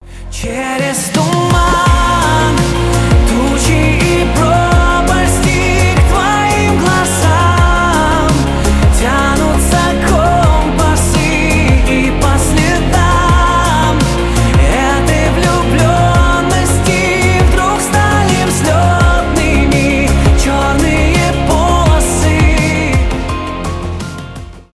в стиле поп
мужской голос